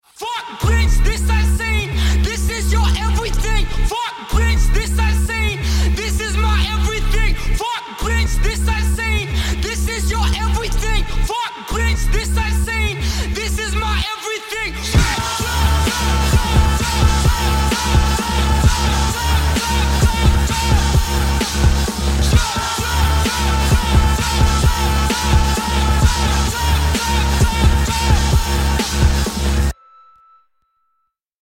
Rap-rock
басы
Rap
electro hop
злые